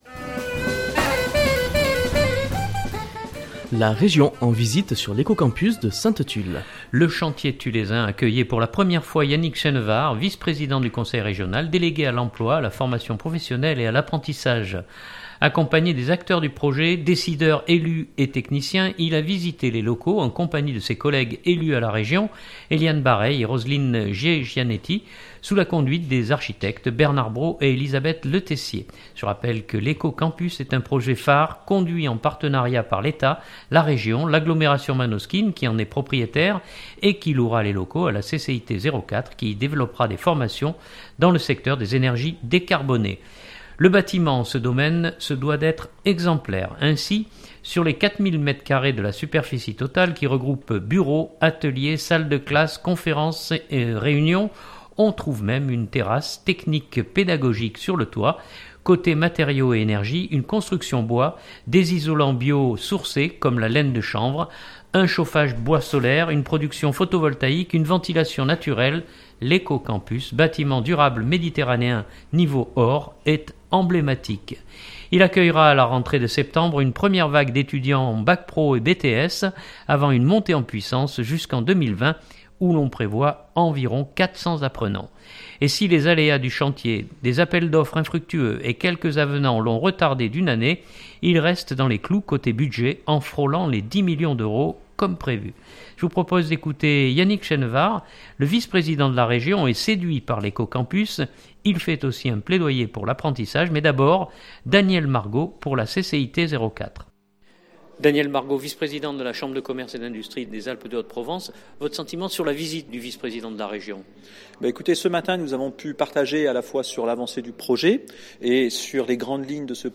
Je vous propose d’écouter Yannick Chenevard ; le vice président de la Région est séduit par l’éco campus ; il fait aussi un plaidoyer pour l’apprentissage.